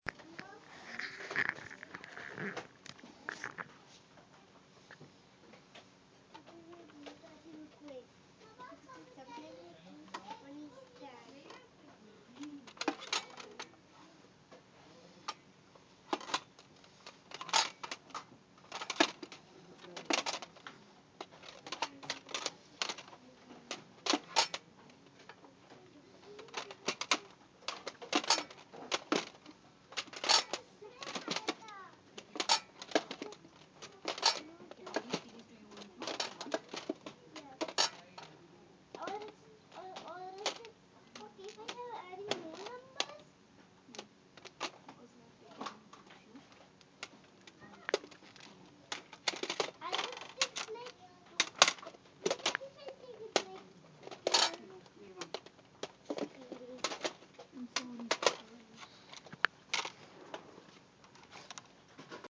field recording
location: dix hills public library
sounds heard: kids talking to their parents, cds hitting each other as i go through the rows of them
dix-hills-library.mp3